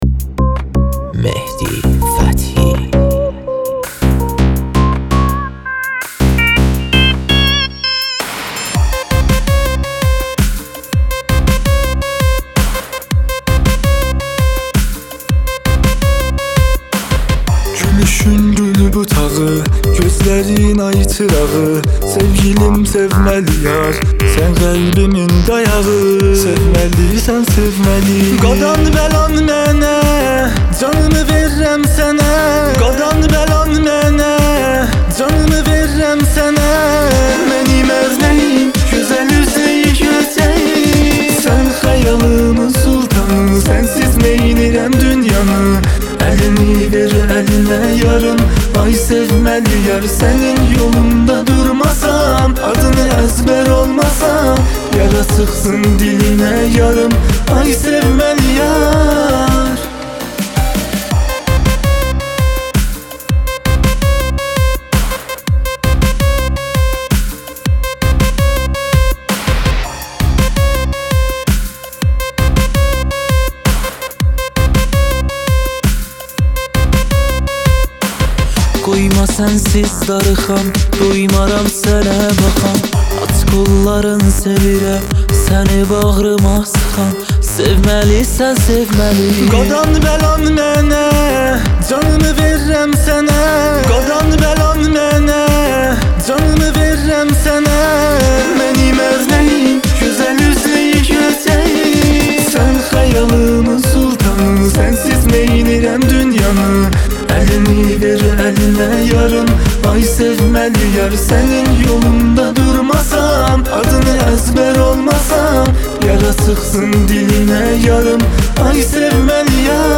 آهنگ آذری و ترکی